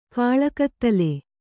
♪ kāḷakattale